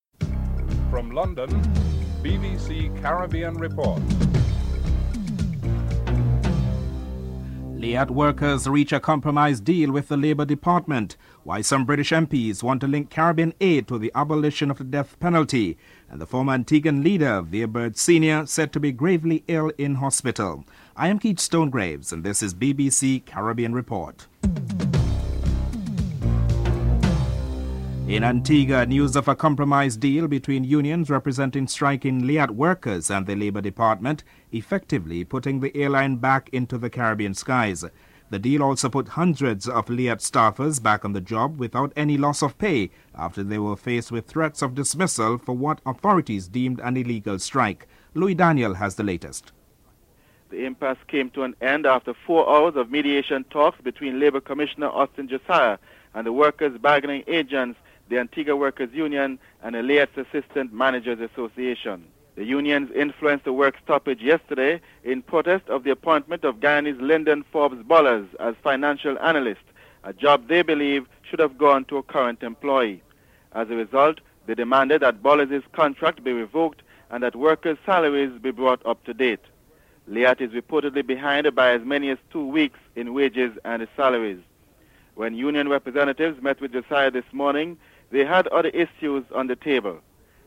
6. Labor Member of Parliament Chris Mullings speaks in the British House of Commons debate on the subject of the death penalty in the Caribbean.